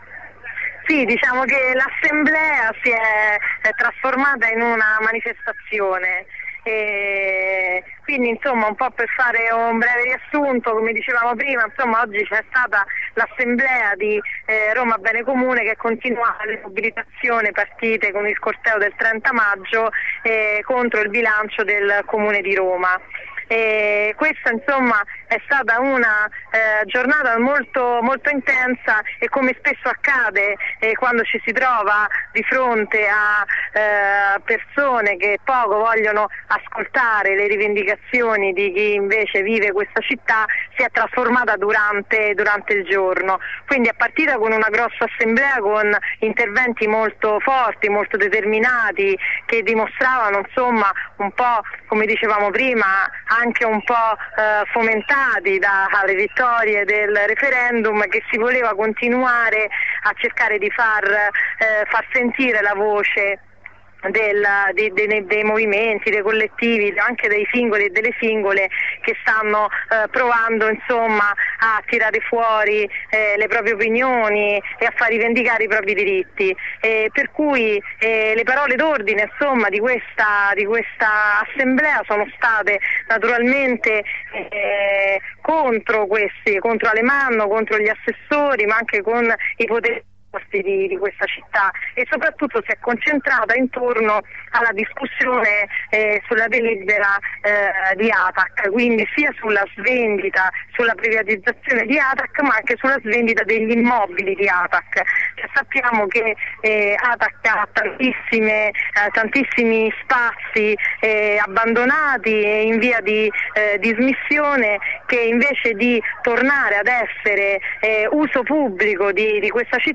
L'assemblea si è così trasformata in blocco stradale e poi in corteo fino al Colosseo. Ascolta un riassunto della giornata e gli appuntamenti per continuare la mobilitazione da una compagna della rete.